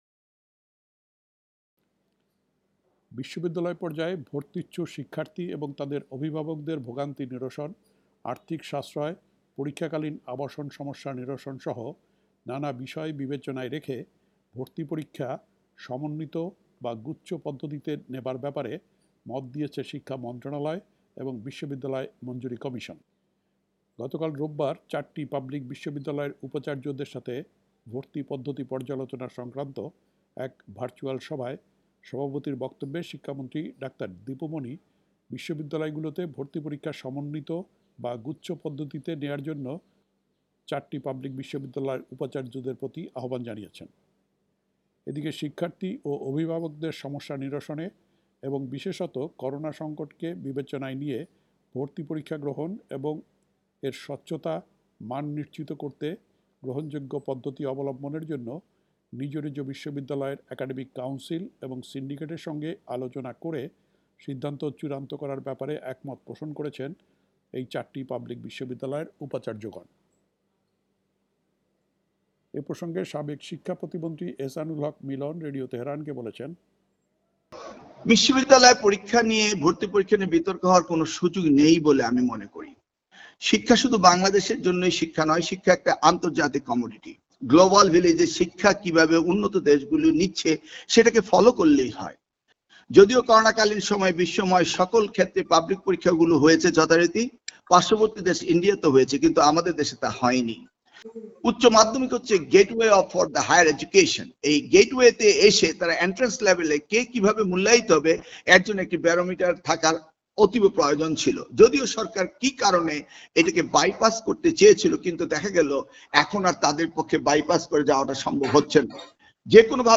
ড. আ ন ম এহসানুল হক মিলন এ প্রসঙ্গে সাবেক শিক্ষা প্রতিমন্ত্রী ড. আ ন ম এহসানুল হক মিলন রেডিও তেহরানকে বলেছেন, শিক্ষা হচ্ছে এখন একটা আন্তর্জাতিক বিষয়।